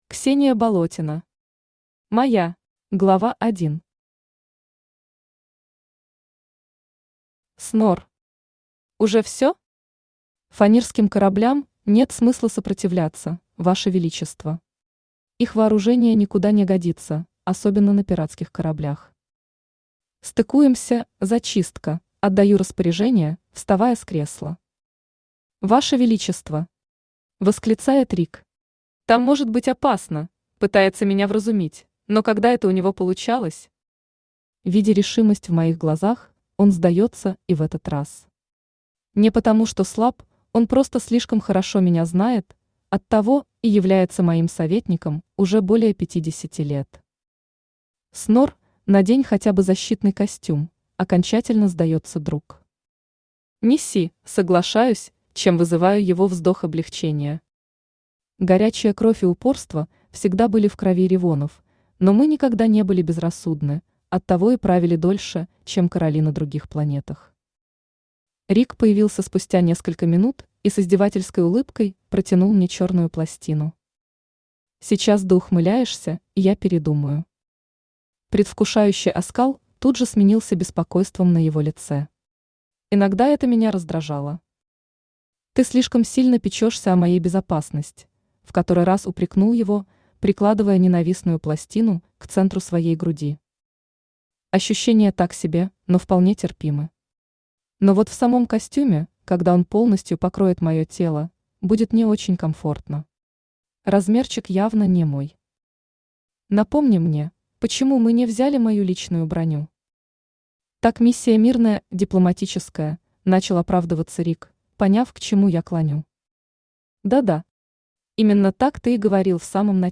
Аудиокнига Моя | Библиотека аудиокниг
Aудиокнига Моя Автор Ксения Болотина Читает аудиокнигу Авточтец ЛитРес.